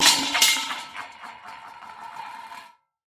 Звук удара железного подноса для пиццы об пол